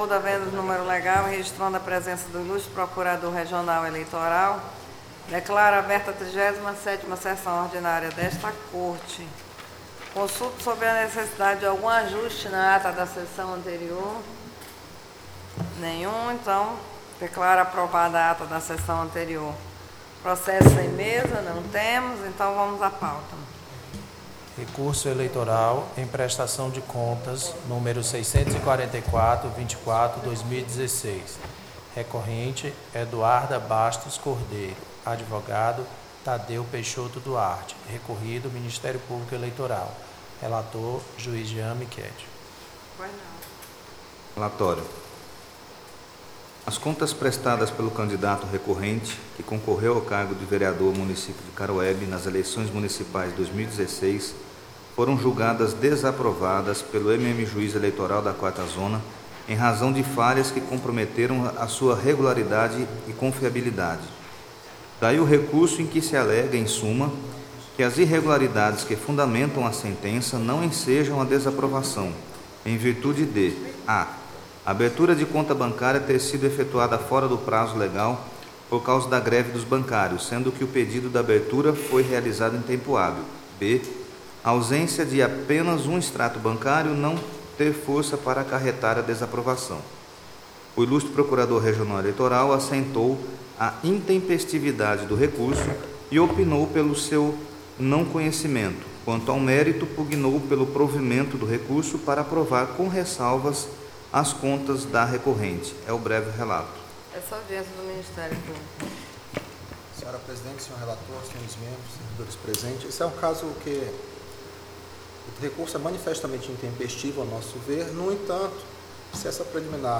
TRE-RR-Audio-37-sessão-ordinaria-06_06_2017